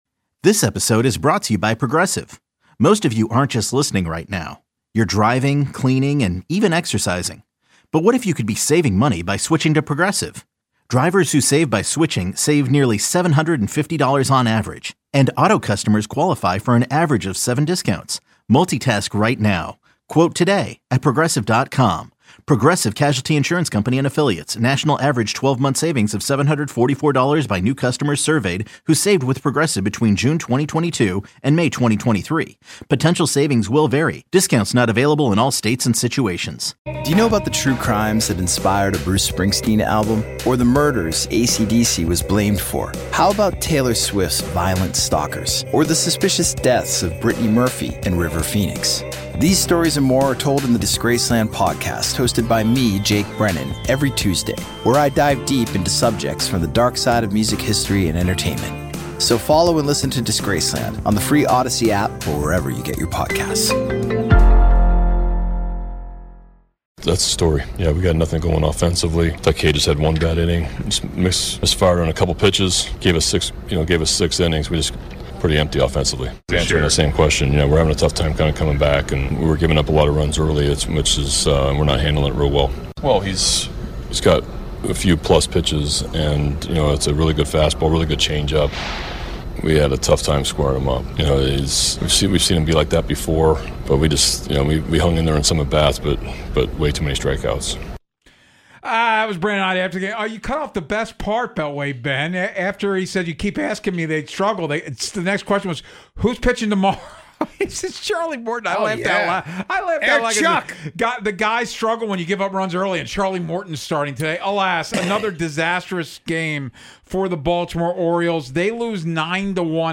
The guys discuss another poor performance by the Orioles at the plate and why things will continue heading in this direction.